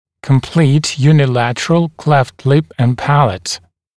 [kəm’pliːt ˌjuːnɪ’lætərəl kleft lɪp ən ‘pælət][кэм’пли:т ˌйу:ни’лэтэрэл клэфт лип эн ‘пэлэт]полная односторонняя расщелина губы и твердого нёба